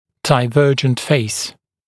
[daɪ’vɜːʤənt feɪs][дай’вё:джэнт фэйс]дивергентный тип лица (длинное лицо)